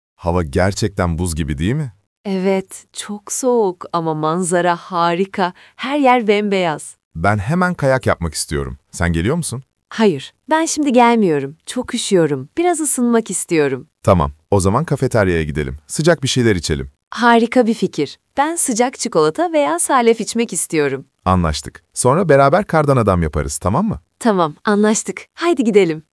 Gestern haben wir über den Uludağ gelesen, heute hören wir zwei Freunden dabei zu, wie sie ihre Zeit dort planen.
Der Dialog